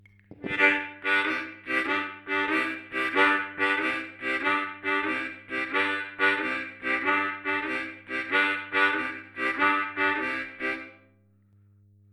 Последней темой, которой бы я хотел коснуться в нашем уроке является ритм шаффл, который, пожалуй, самый распространенный в блюзе.
Каждая четверть носит триольный характер, т.е. делится на три равных ноты.